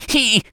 rabbit_squeak_hurt_01.wav